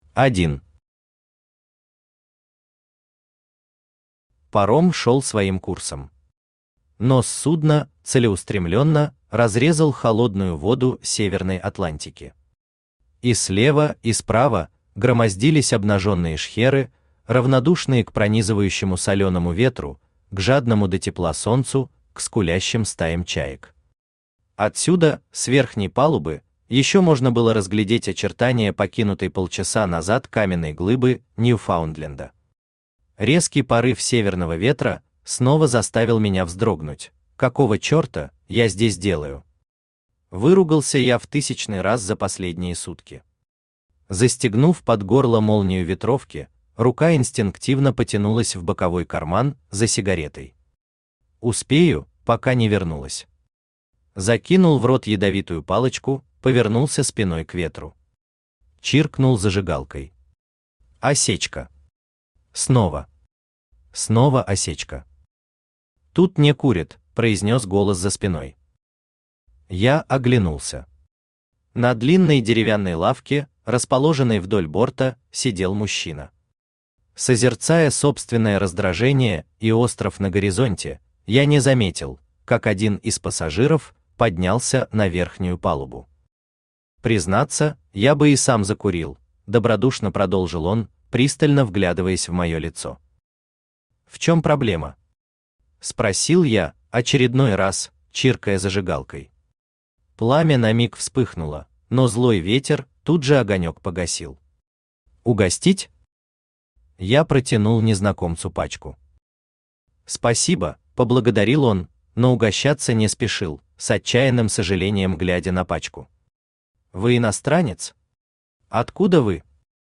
Aудиокнига Клональ Автор ШаМаШ БраМиН Читает аудиокнигу Авточтец ЛитРес.